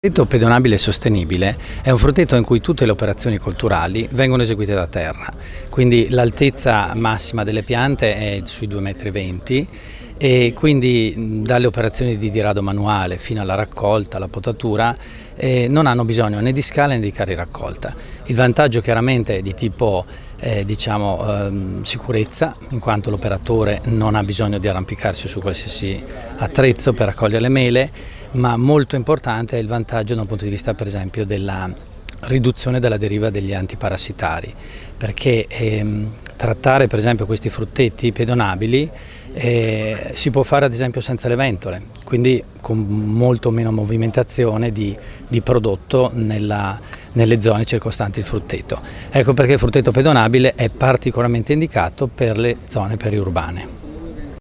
Successo alla giornata tecnica dedicata alla frutticoltura di montagna a Maso Maiano di Cles